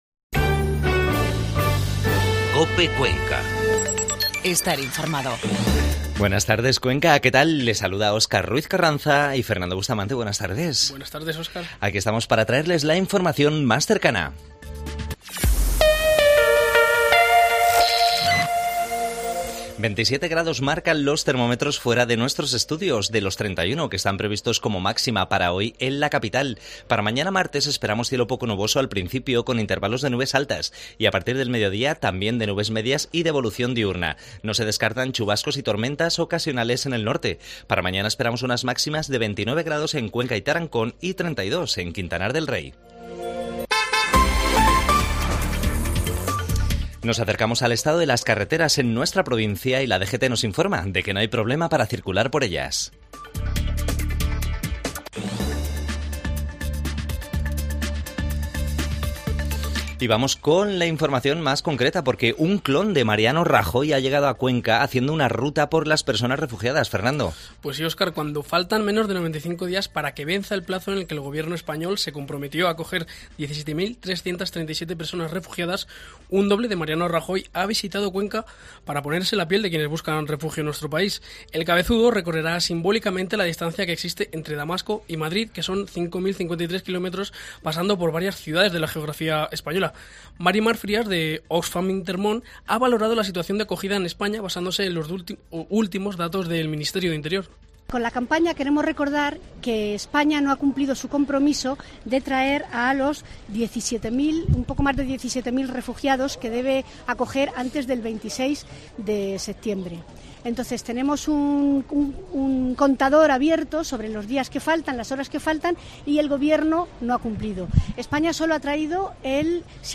Infomativo mediodia 26 junio